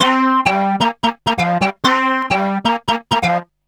___BOTTLES 3.wav